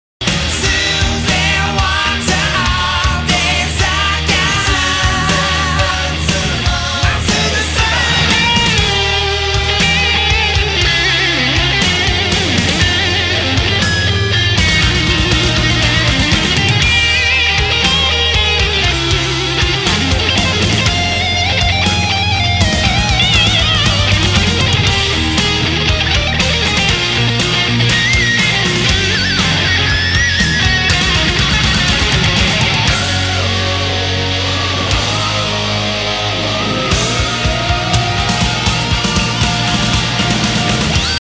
↓カラオケ適用前